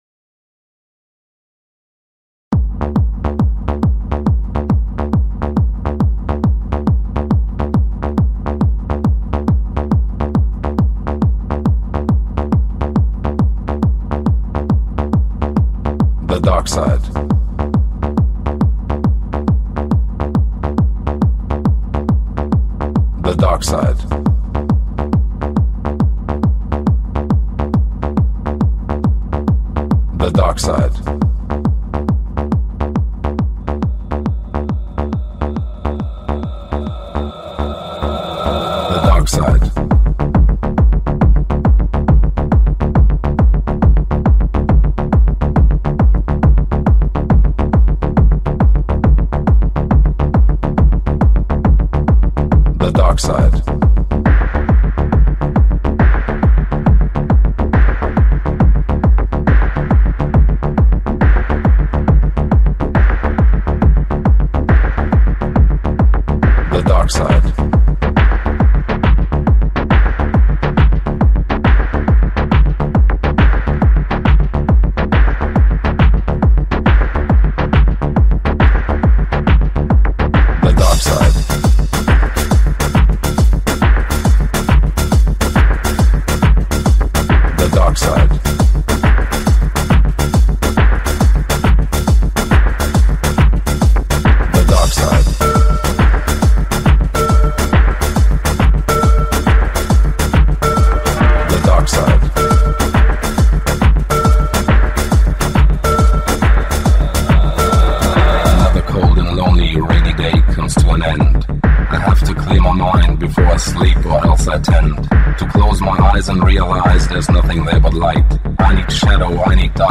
Жанр: Hard Trance